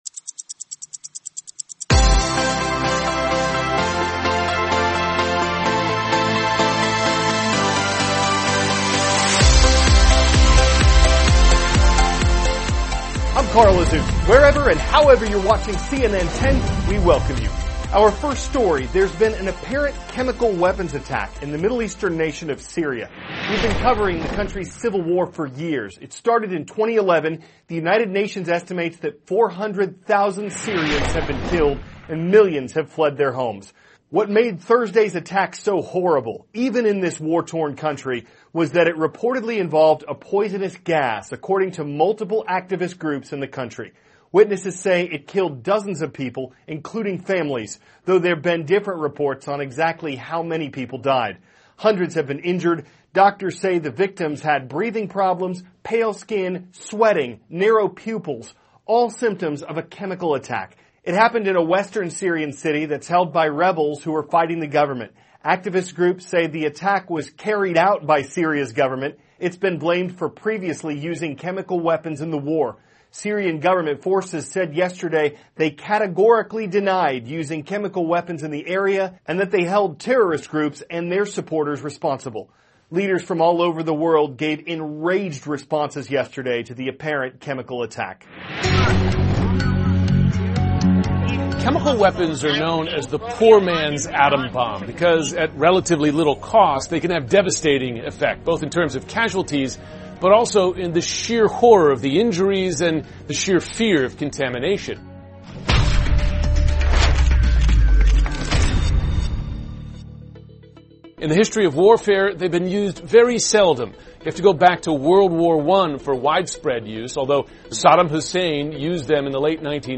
*** CARL AZUZ, cnn 10 ANCHOR: I`m Carl Azuz.